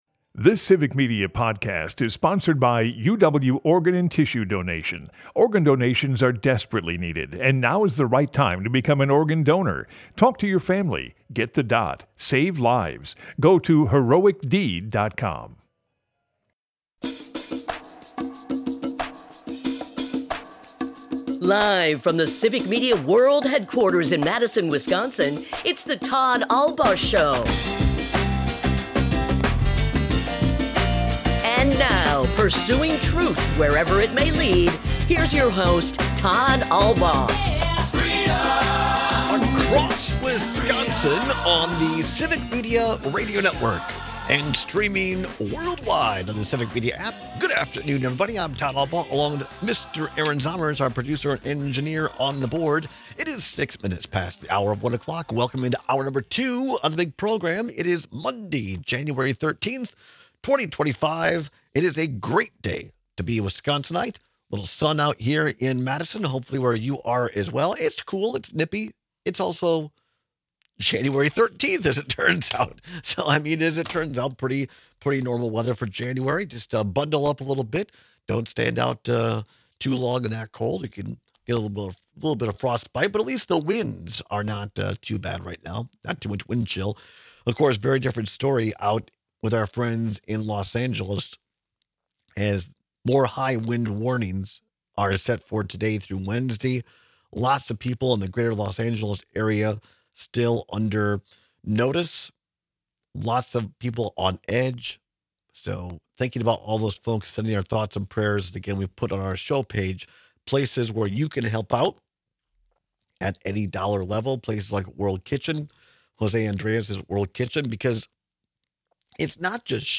We take some very passionate calls debating whether it’s worse to be dangerous or annoying.